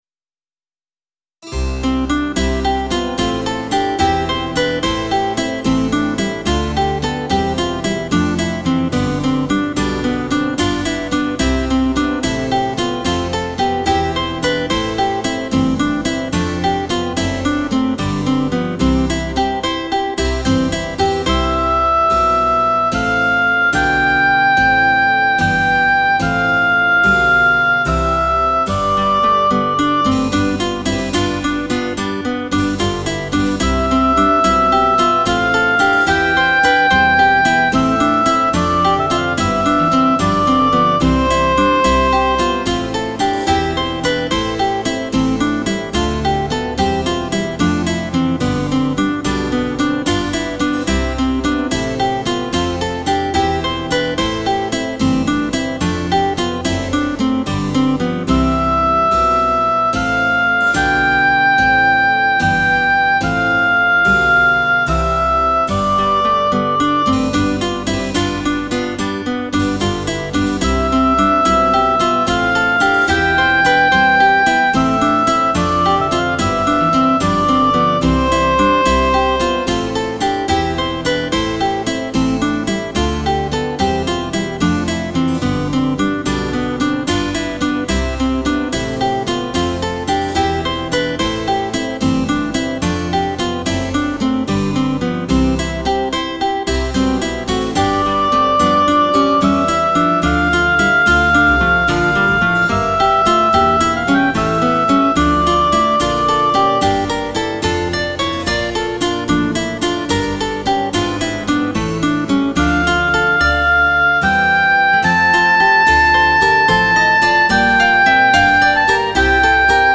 für Solo und Gitarrenquartett (Bass und Continuo ad lib.)